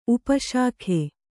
♪ upa śakhe